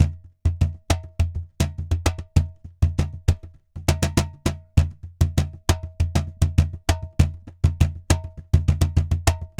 Cajon_Baion 100_1.wav